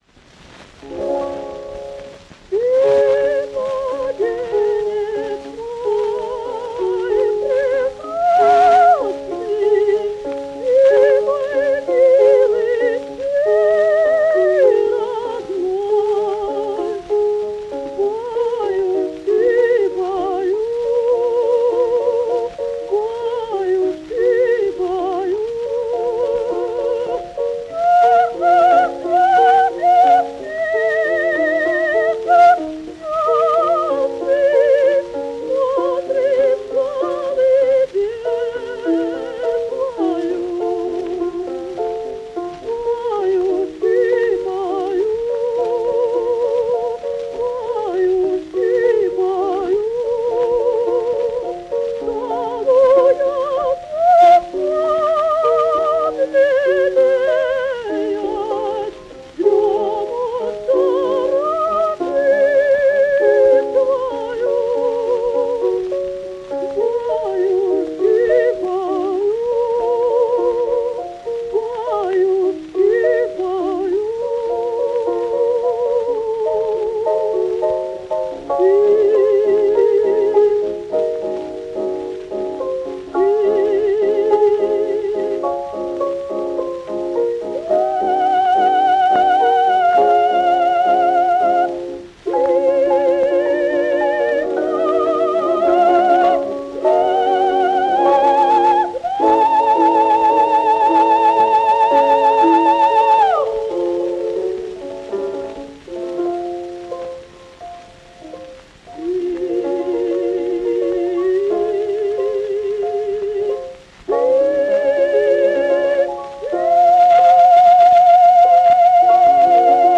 Ермо́ленко-Ю́жина Ната́лья Степа́новна (урождённая Плуговская, сценический псевдоним Ермоленко, в замужестве Южина) (1881, Киев – 1937, Париж), российская певица (драматическое сопрано), заслуженная артистка Республики (1920).
Наталья Ермоленко-Южина в партии Лизы в опере «Пиковая дама» П. И. ЧайковскогоНаталья Ермоленко-Южина в партии Лизы в опере «Пиковая дама» П. И. Чайковского. 1911.Наталья Ермоленко-Южина в партии Лизы в опере «Пиковая дама» П. И. Чайковского. 1911.Обладала исключительным по силе и красоте «сочным» драматическим сопрано широкого диапазона, позволявшим ей исполнять также партии для меццо-сопрано (Кармен в , Марина Мнишек в опере «Борис Годунов» Мусоргского).
Записывалась на грампластинки в 1903–1912 гг. в Петербурге и Москве (фирмы «В. И. Ребиков», «Пате», «Граммофон»).